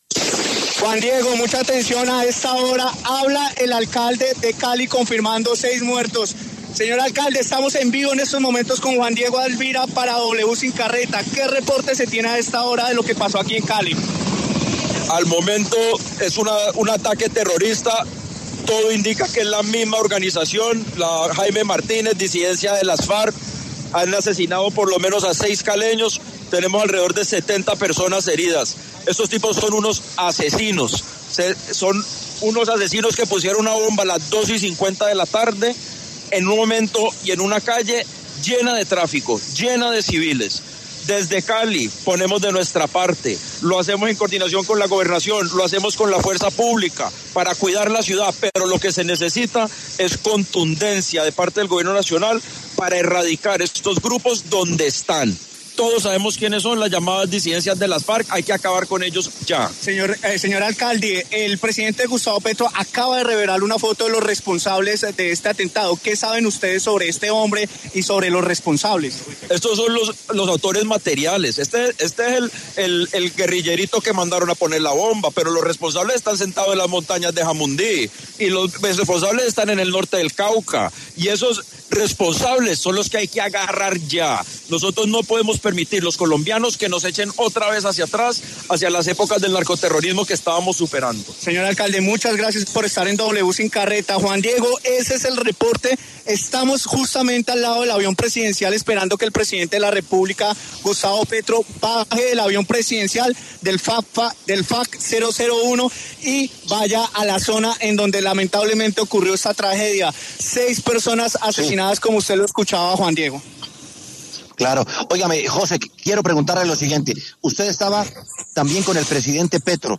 El alcalde de Cali, Alejandro Éder, pasó por los micrófonos de W Sin Carreta para hablar sobre el atentado con carro-bomba en la capital del Valle que dejó varias personas muertas y más de 70 heridos. Además, aseguró que los responsables de este hecho están en las montañas de Jamundí y en el norte de Cauca.